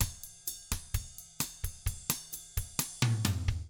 129BOSSAF4-L.wav